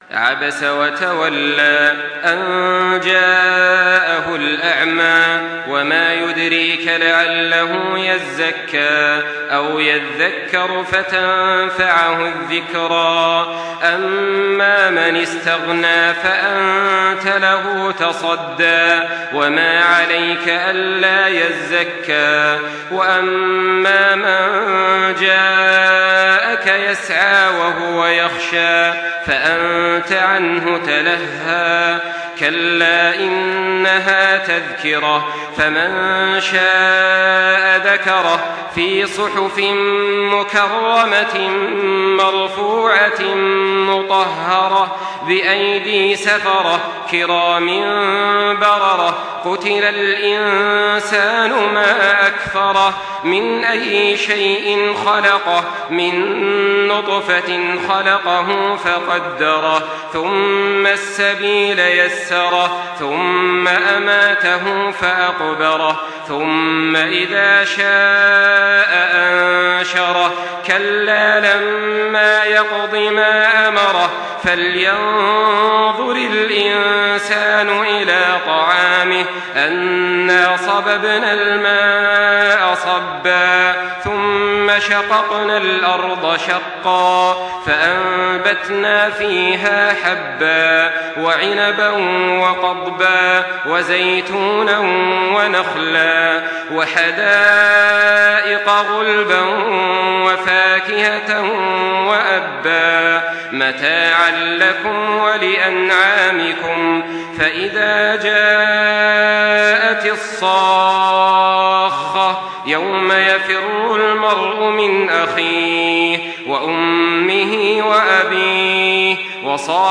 Surah Abese MP3 by Makkah Taraweeh 1424 in Hafs An Asim narration.
Murattal